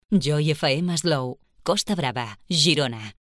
Indicatiu amb la freqüència d'FM.